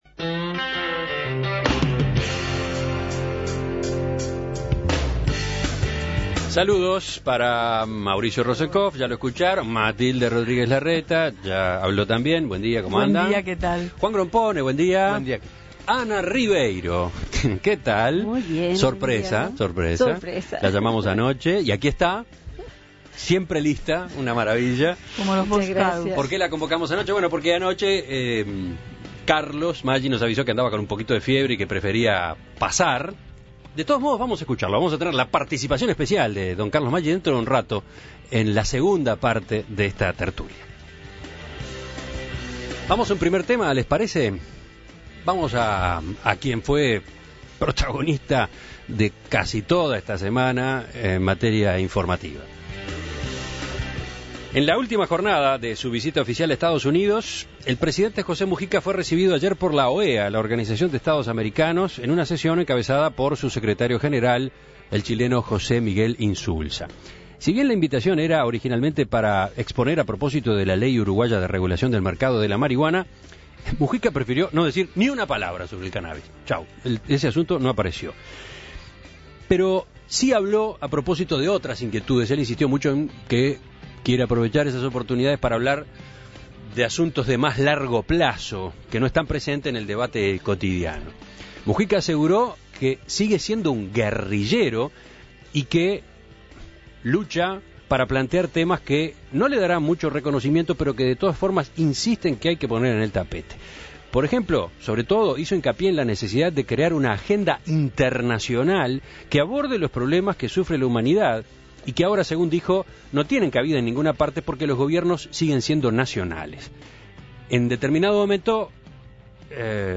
En el encuentro encabezado por el secretario general de la OEA, el chileno José Miguel Insulza, el presidente aseguró que sigue siendo "un guerrillero" y dijo que suele plantear temas que no le darán mucho reconocimiento, pero que de todas formas insiste en poner sobre el tapete